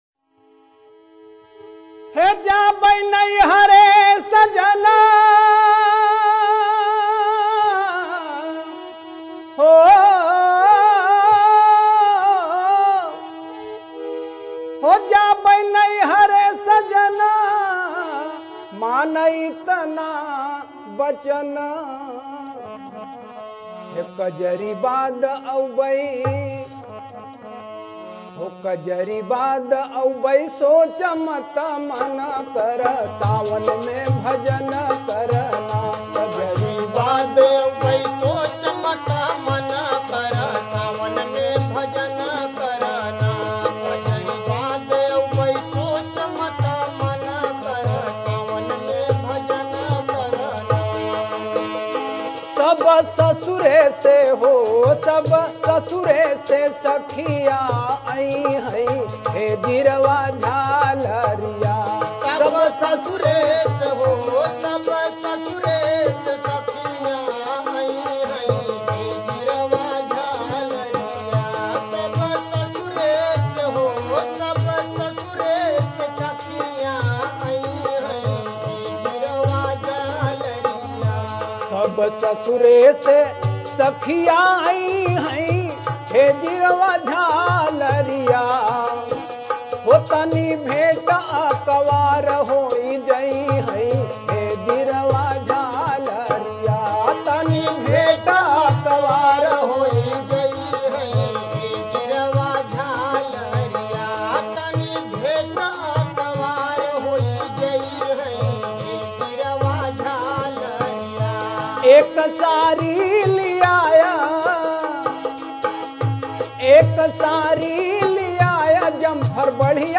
Rajasthani Songs
Kajri